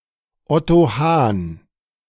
Otto Hahn (German: [ˈɔtoː ˈhaːn]
De-Otto_Hahn.ogg.mp3